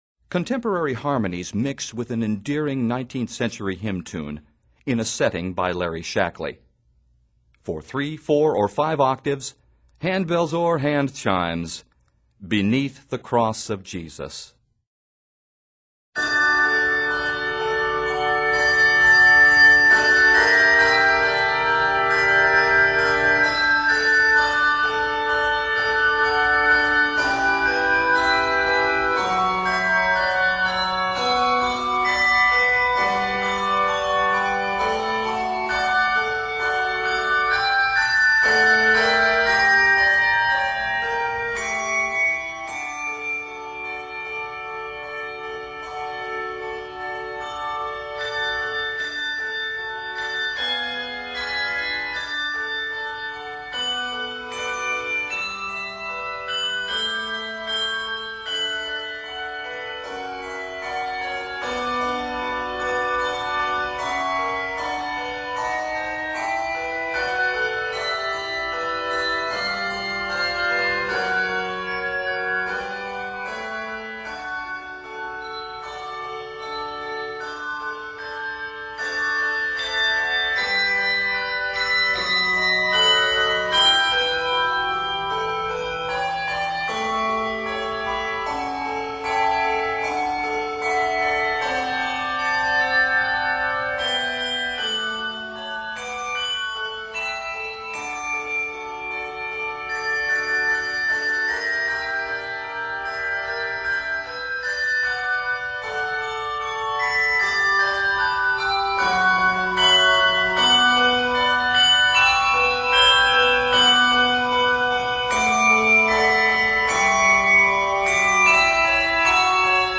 The piece is arranged in D Major and is 67.